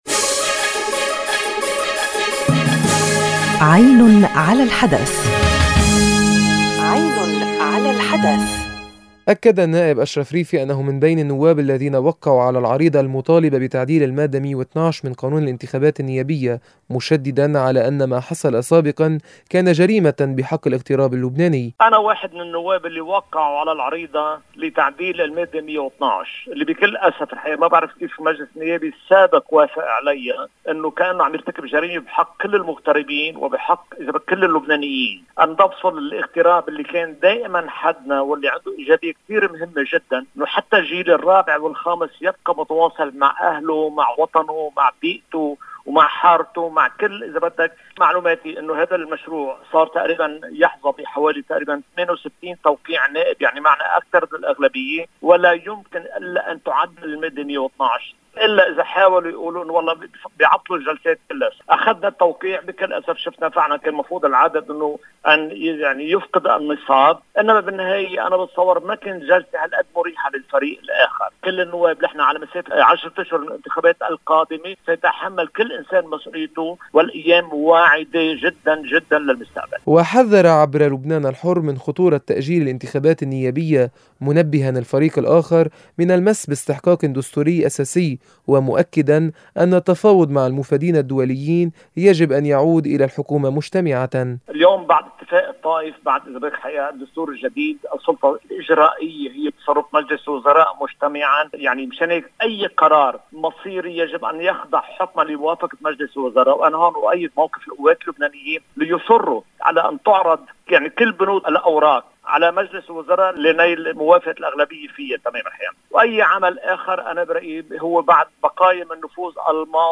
أكّد النائب أشرف ريفي، في حديث لـ”لبنان الحرّ”، أنّه من بين النواب الذين وقّعوا على العريضة المطالبة بتعديل المادة 112 من قانون الانتخابات النيابية، مشدّدًا على أنّ “ما حصل سابقًا كان جريمة بحقّ الاغتراب اللبناني، وبالتالي مخالفة بحقّ كلّ اللبنانيين، هذه المادة تعزل المغتربين الذين وقفوا دائمًا إلى جانب وطنهم، وتُهدّد استمرارية تواصل الأجيال الرابعة والخامسة منهم مع جذورهم وأهلهم وبيئتهم”.